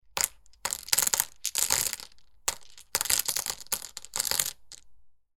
Mahjong-tiles-sound-effect.mp3